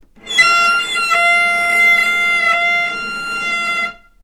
healing-soundscapes/Sound Banks/HSS_OP_Pack/Strings/cello/sul-ponticello/vc_sp-F5-ff.AIF at bf8b0d83acd083cad68aa8590bc4568aa0baec05